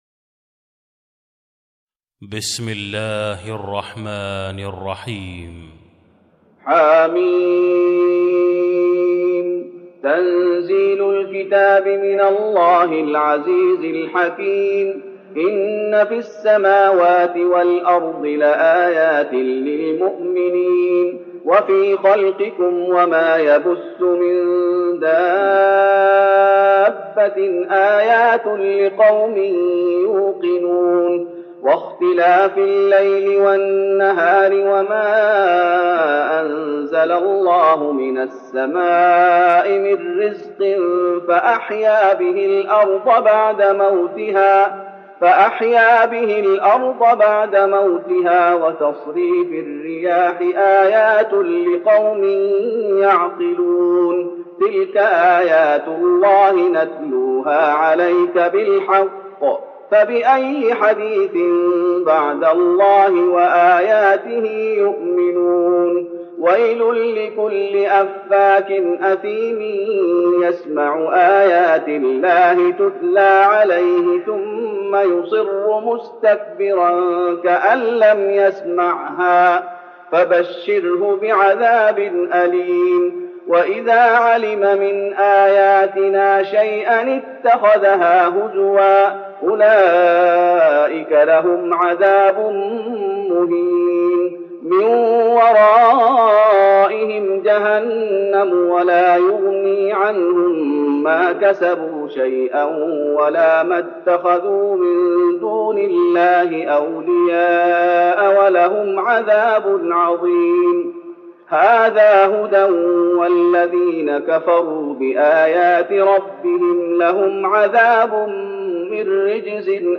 تراويح رمضان 1413هـ من سورة الجاثية Taraweeh Ramadan 1413H from Surah Al-Jaathiya > تراويح الشيخ محمد أيوب بالنبوي 1413 🕌 > التراويح - تلاوات الحرمين